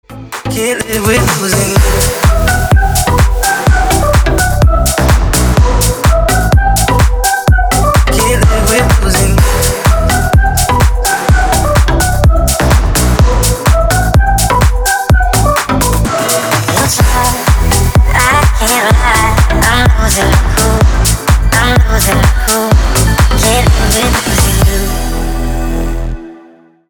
Поп Музыка
клубные